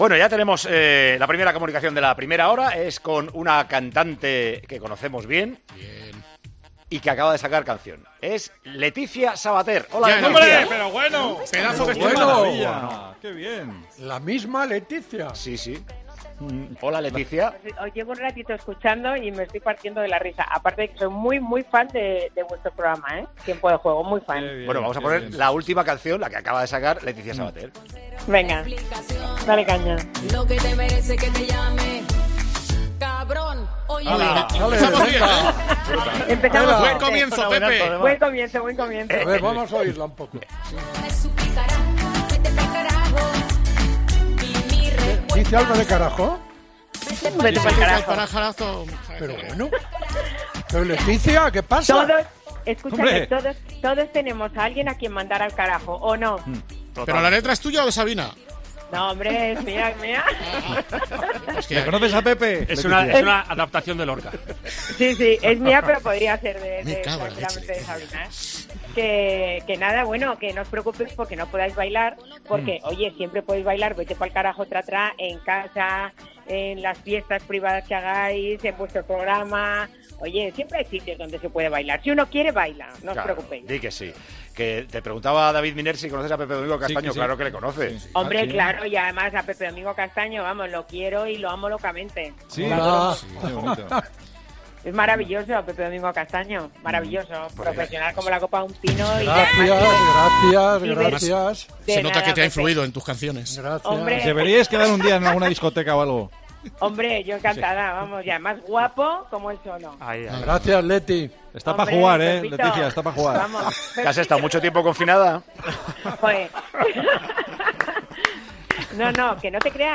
La cantante ha contado en Tiempo de Juego qué proyectos profesionales tiene en un futuro próximo y cómo va a ser la gira de este verano con su nuevo éxito.
Con Paco González, Manolo Lama y Juanma Castaño